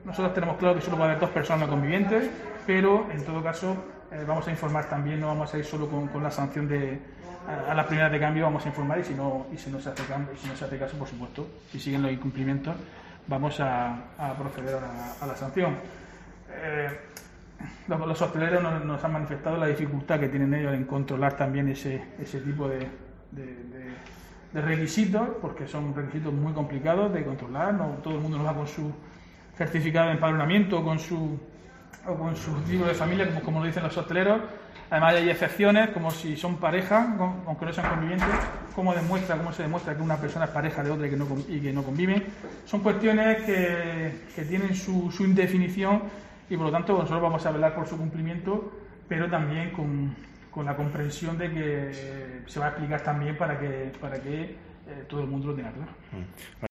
Diego José Mateos, alcalde de Lorca sobre sanciones